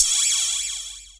Index of /90_sSampleCDs/Club_Techno/Percussion/Cymbal
Ride_03.wav